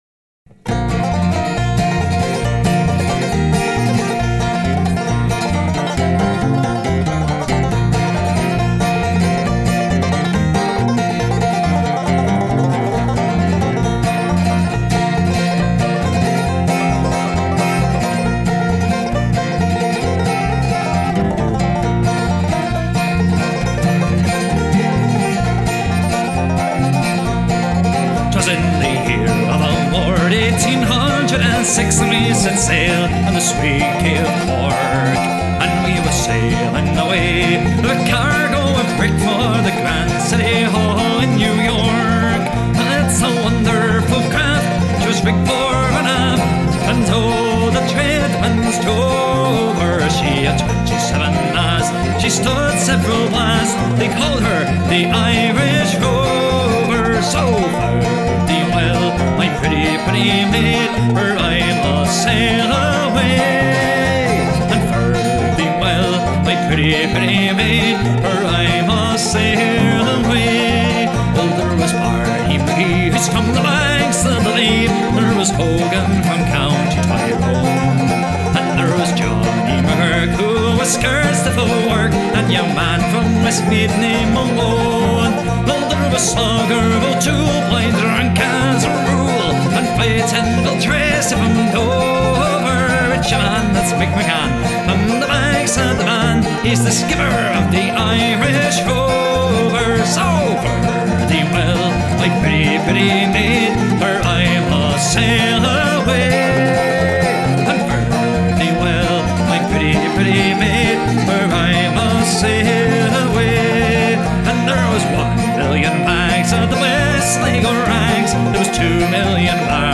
This UK Irish Band consists of a father and a son duo on most nights (occasionally they have guest artists with them). Instruments played at their performances are Guitar, tennor and G-banjo, Tin whistle, and many more.